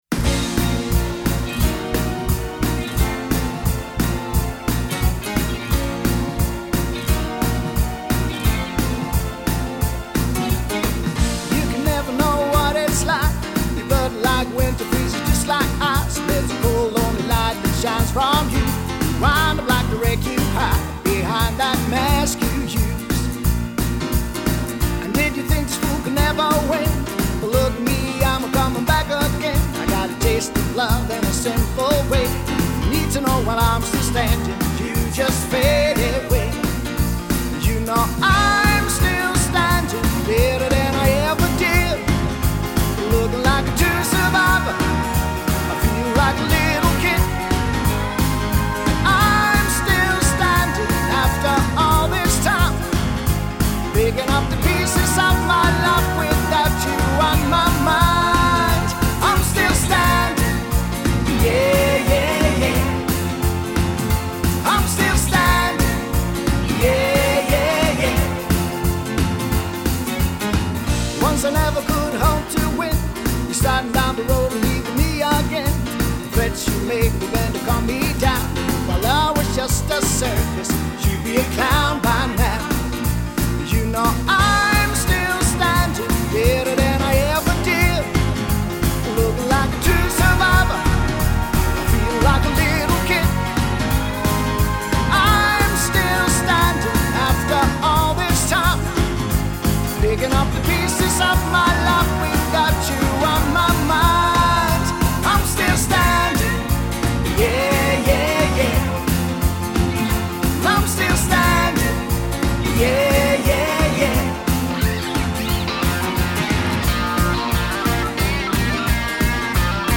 Pop/Rock Duo